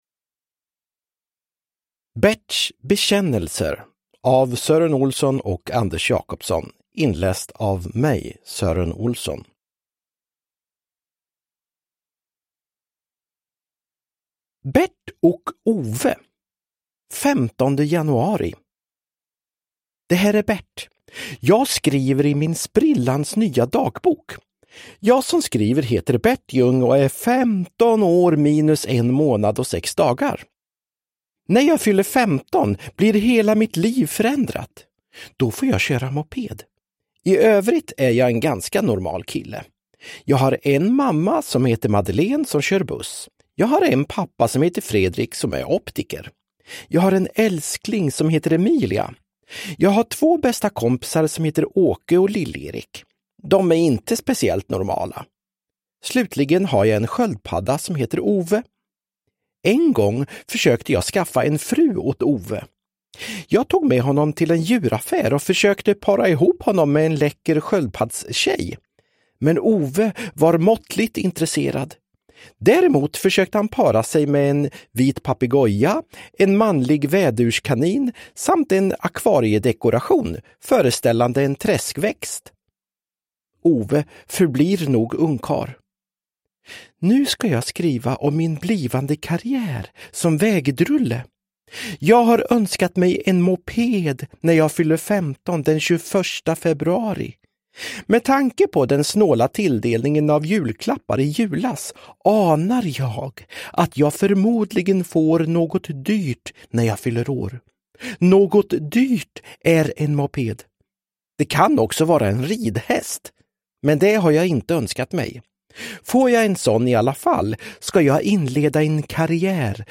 Berts bekännelser – Ljudbok – Laddas ner
Uppläsare: Sören Olsson